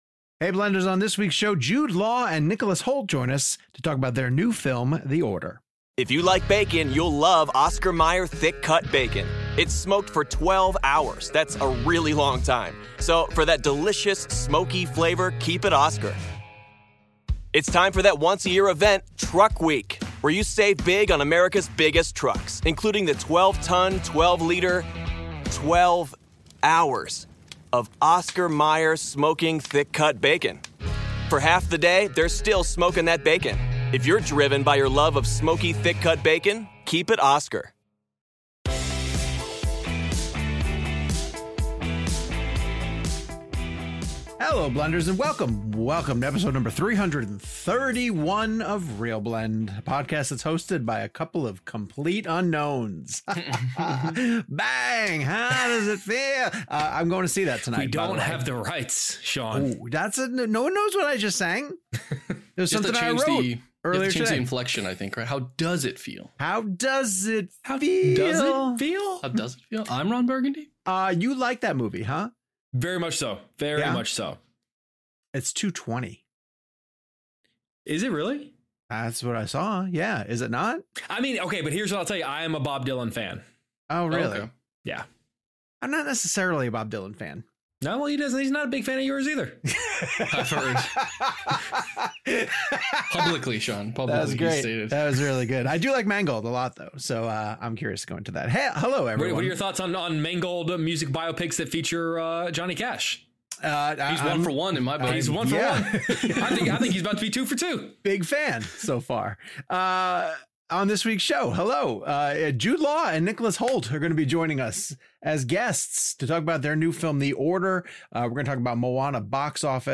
Jude Law & Nicholas Hoult Interview | 'The Order,' 'Superman,' 'Road To Perdition' & More (; 06 Dec 2024) | Padverb